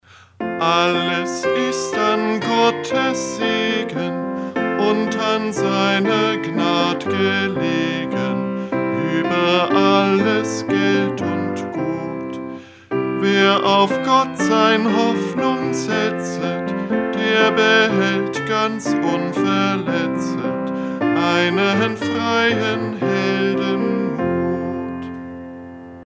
Melodie: 1691, Johann Löhner (EG 352);
Liedvortrag